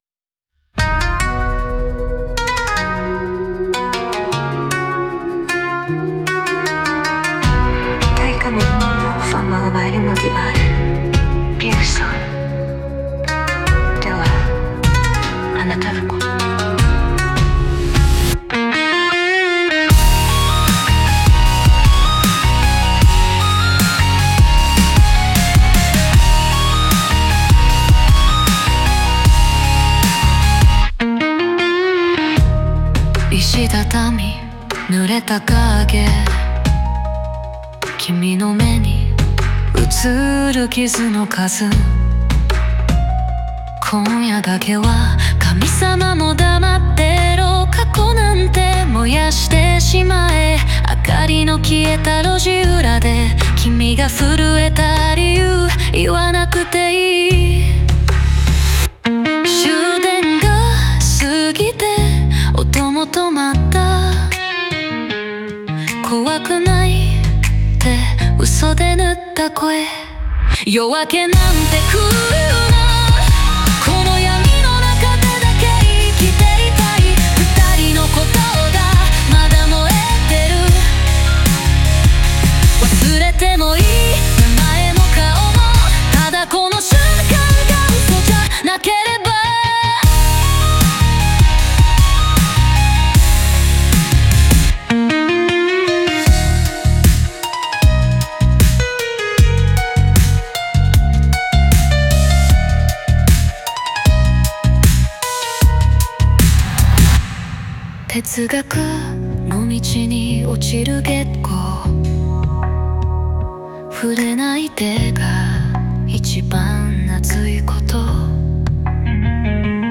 オリジナル曲♪
激しい音と静寂の対比が、内面の葛藤と切なさを強調しています。